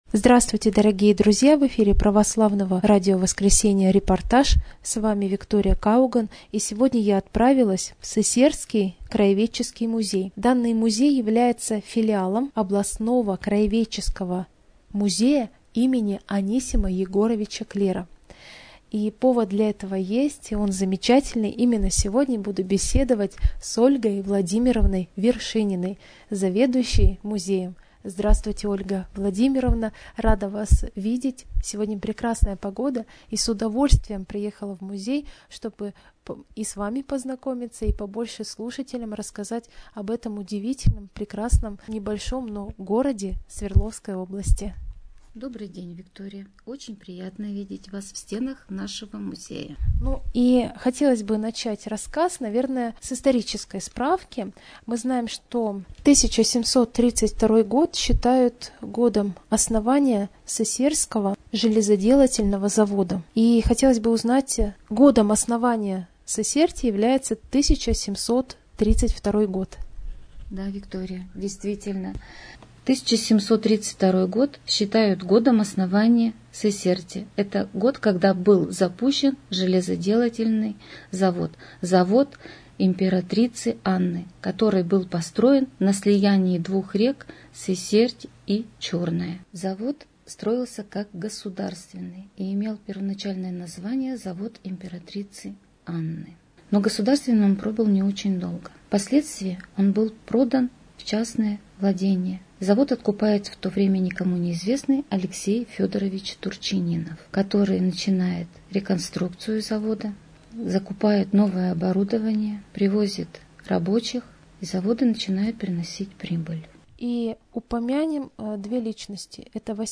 30 декабря 2025 Интервью с Владимиром Герасименко, экс-главой городского округа Арамиль.